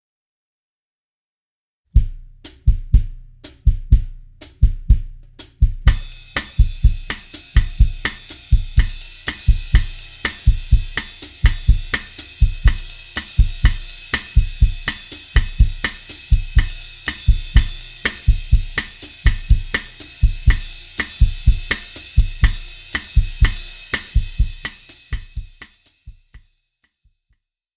Besetzung: Schlagzeug
21 - Bossa-Nova-Groove 2